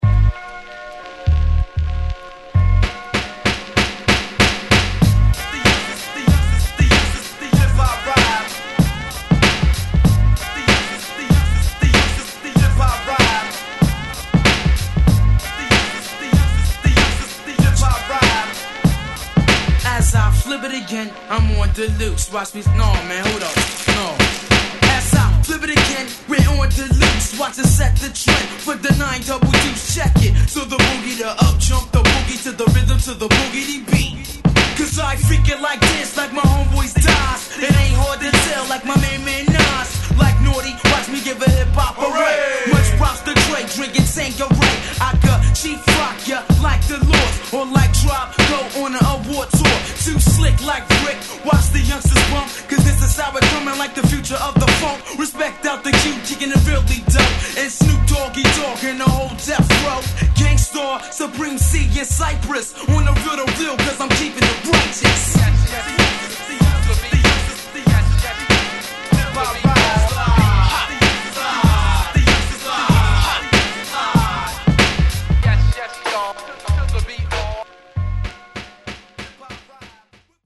90's Hip Hop Classic!!